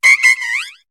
Cri de Nanméouïe dans Pokémon HOME.